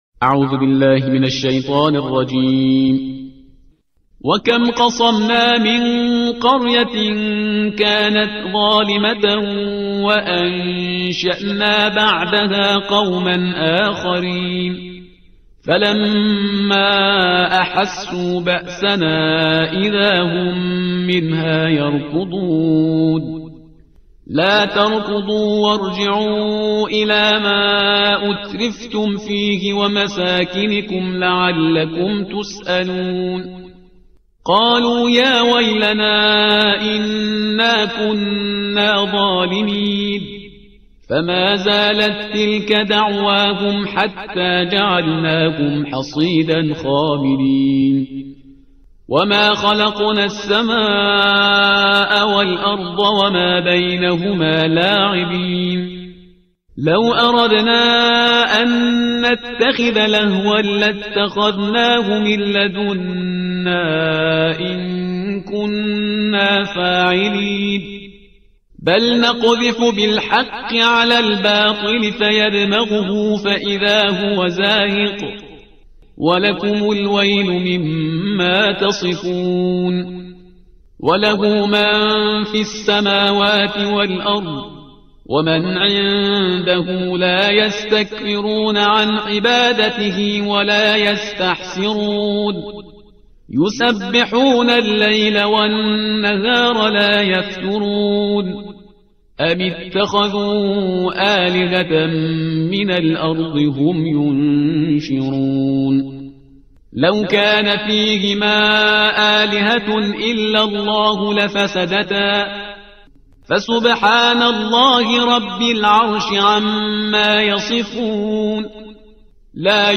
ترتیل صفحه 323 قرآن – جزء هفدهم